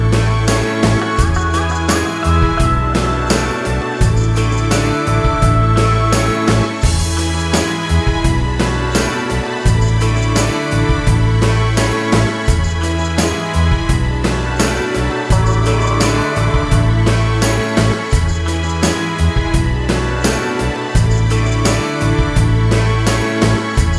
Cut Down Indie / Alternative 4:03 Buy £1.50